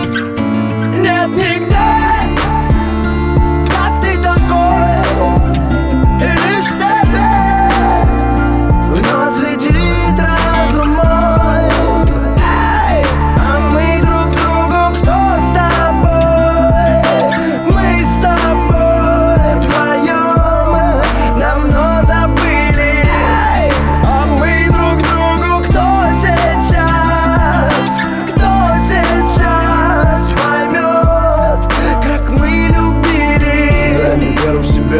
Жанр: Рэп и хип-хоп / Иностранный рэп и хип-хоп / Русские